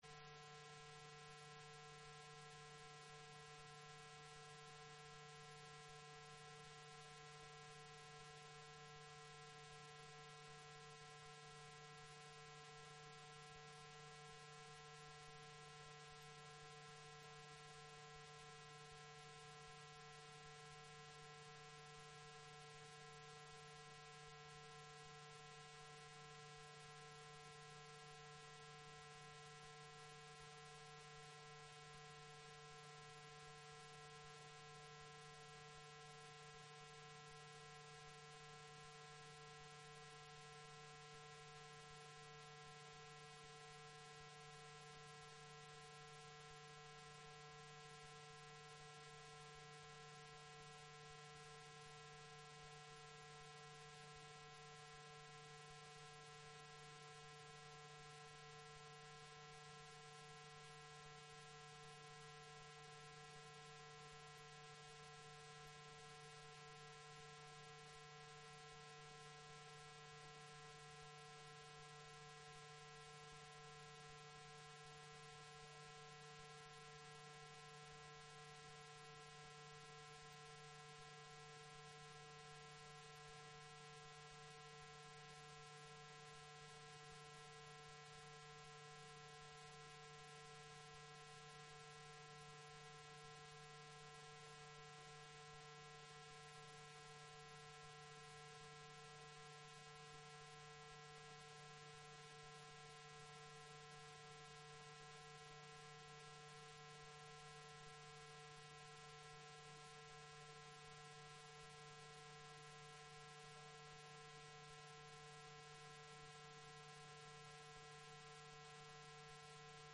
Sermons - Highland Baptist Church